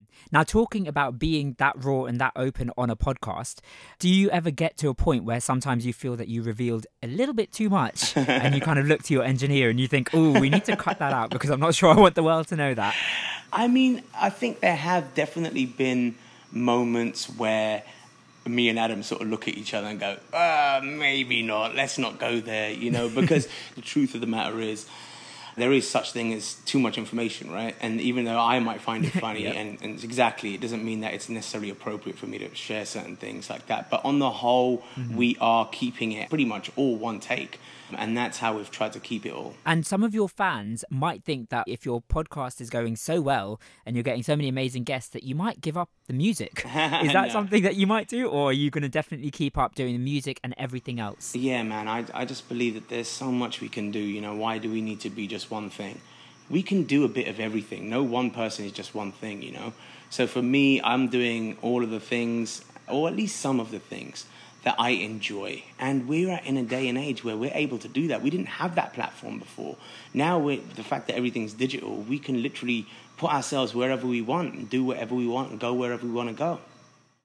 spoke to R&B artist Jay Sean